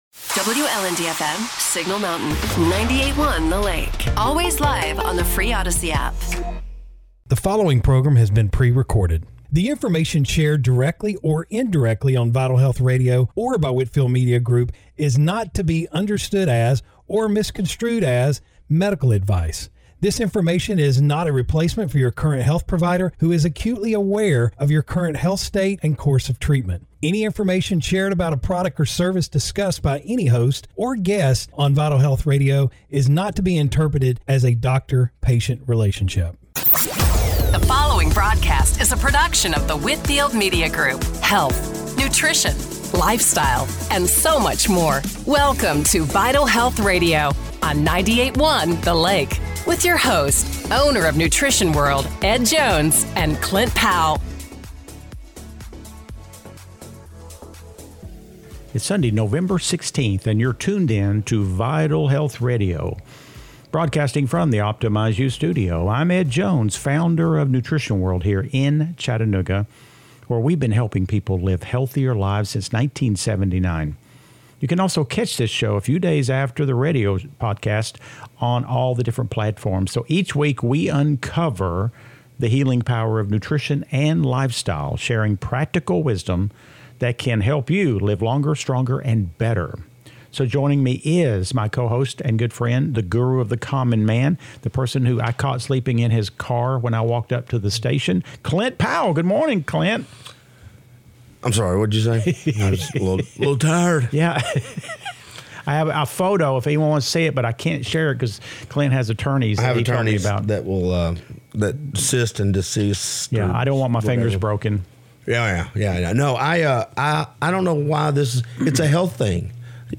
Broadcasting from the Optimize u Studio